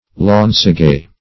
Launcegaye \Launce"gaye`\, n.